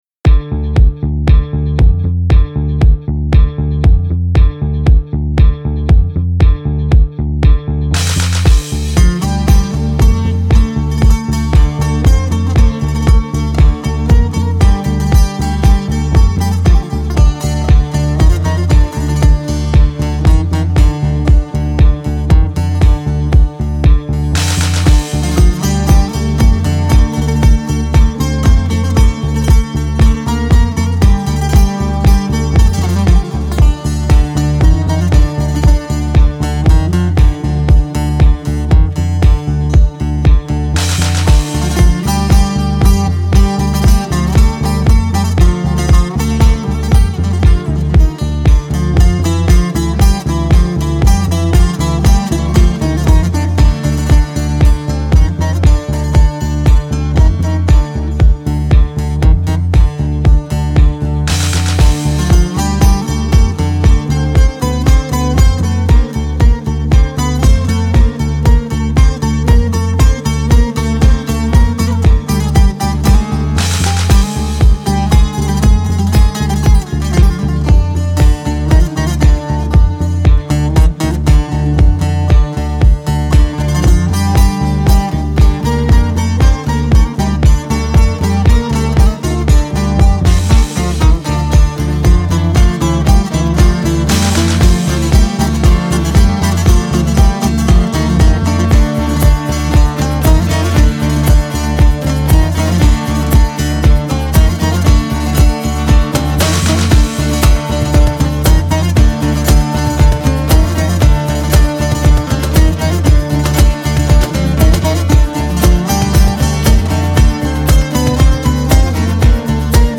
امید‌بخش , بومی و محلی , رقص , ملل
باغلاما موسیقی بی کلام محلی